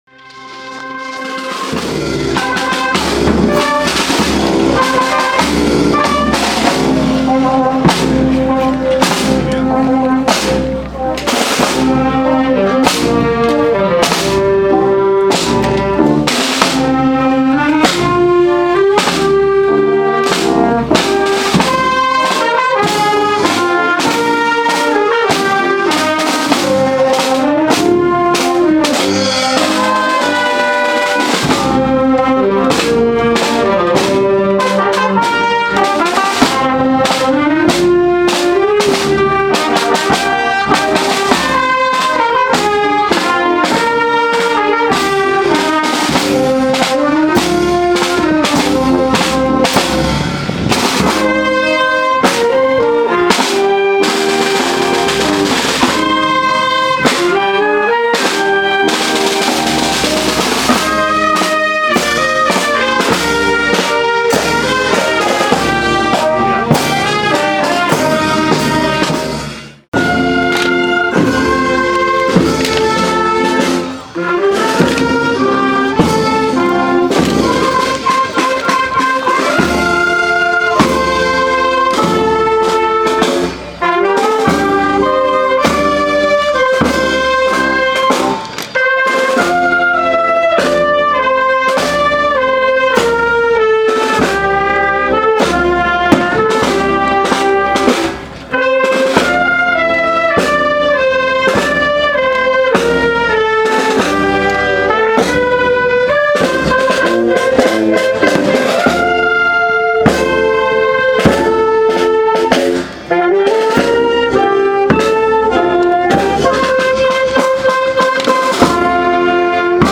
El pasado domingo 17 de julio tuvo lugar una Solemne Procesión en honor a la Virgen del Carmen por las calles de la pedanía de El Paretón, acompañados, como cada año, por numerosos vecinos, autoridades y niños vestidos con sus trajes de Primera Comunión.
La banda de música "Asociación Amigos de la Música de El Paretón" acompañó a la comitiva, interpretando diferentes marchas procesionales como "Triunfal", "A la voz de capataz", "Pescador de Hombres" o "El Evangelista".